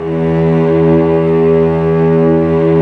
Index of /90_sSampleCDs/Roland LCDP13 String Sections/STR_Vcs II/STR_Vcs6 f Amb